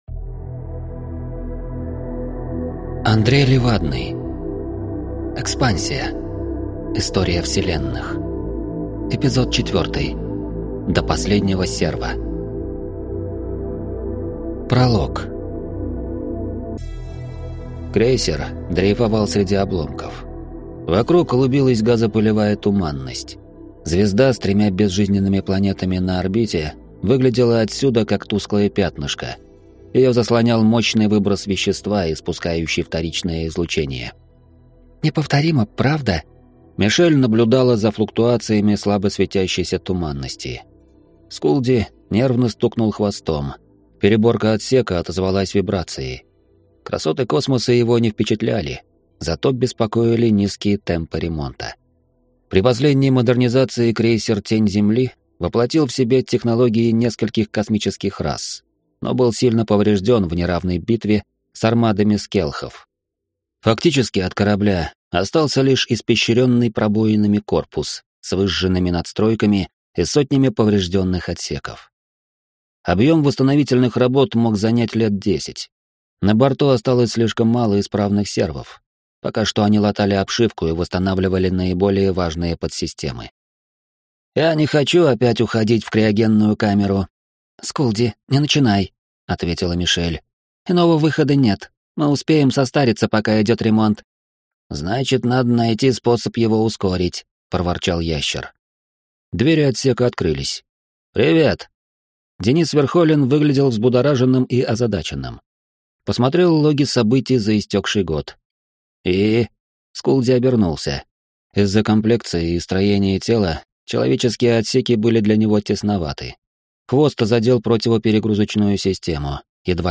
Аудиокнига До последнего серва | Библиотека аудиокниг